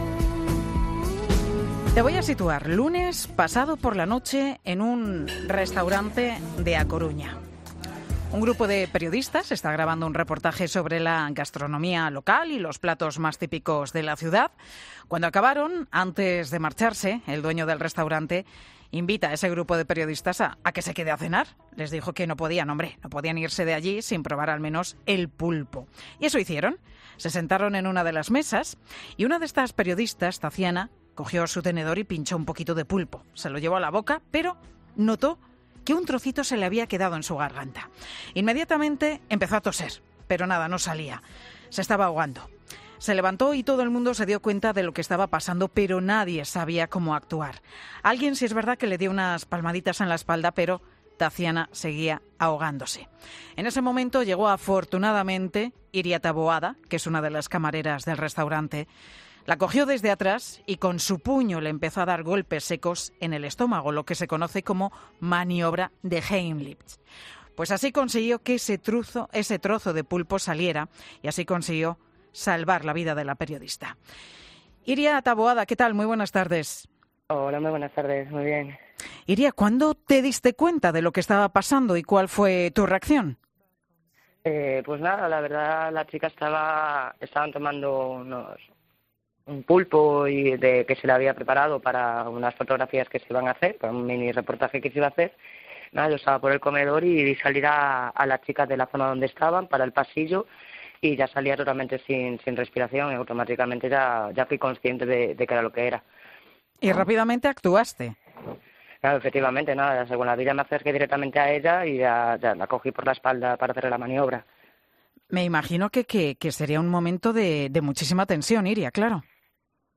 'Mediodía COPE' entrevista a una camarera que salvó la vida de una clienta